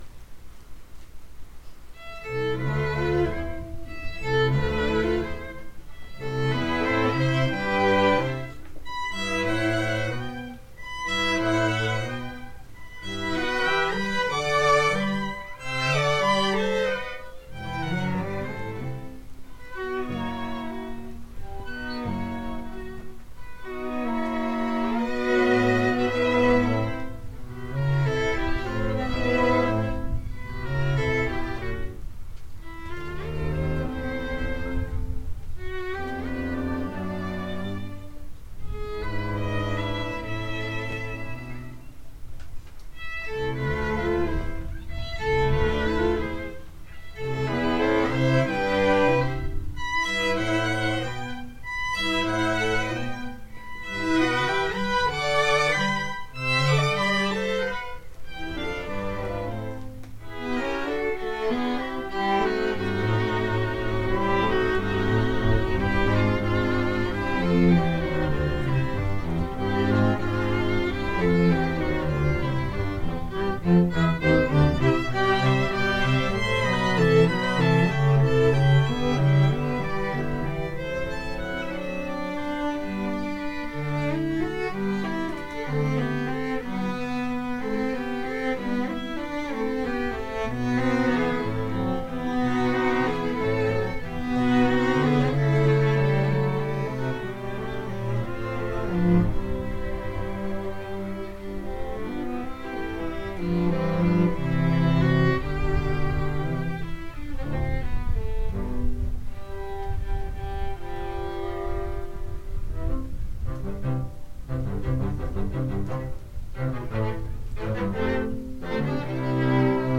Chamber Groups
Haydn Piano Trio no. 39
Ernest MacMillan String Quartet
Beethoven Piano Trio op. 70 no. 2